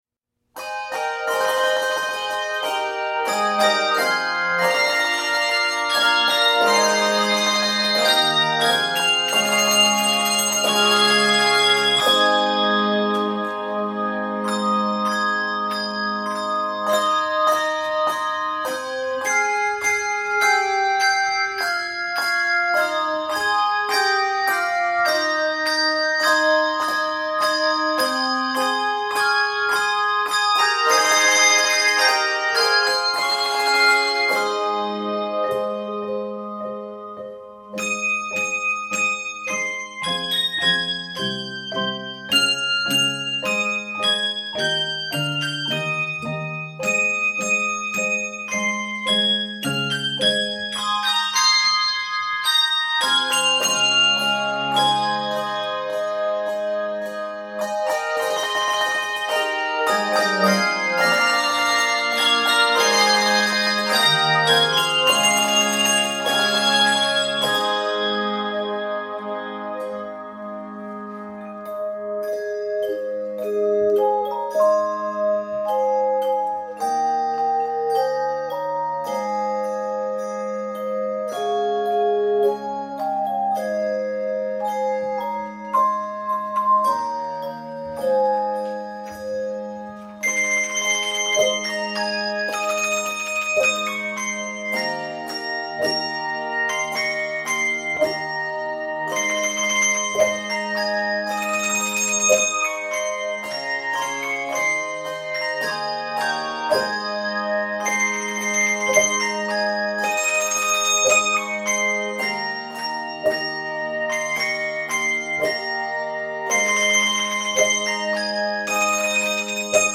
is a 3, 4, or 5 octave medley